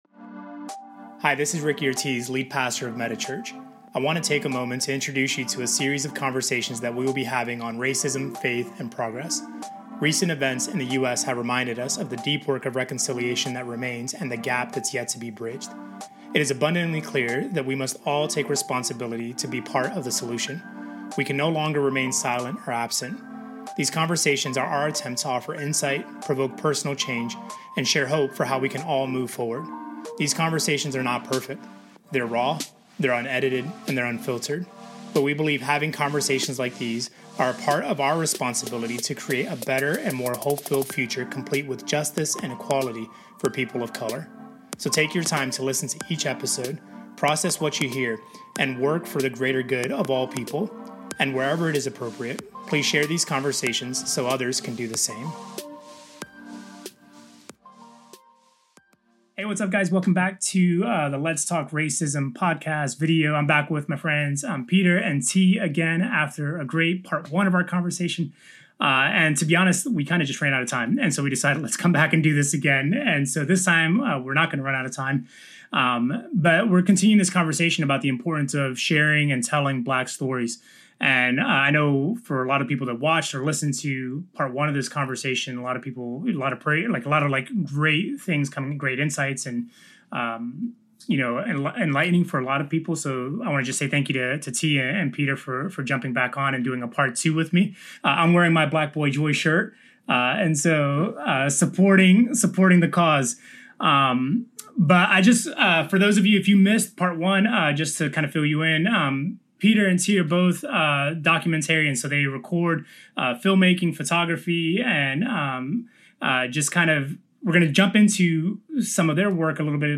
In this conversation, we discuss the importance of telling Black stories accurately through film, photography, and other media and the role storytelling plays in affecting positive change.